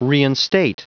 Prononciation du mot reinstate en anglais (fichier audio)
Prononciation du mot : reinstate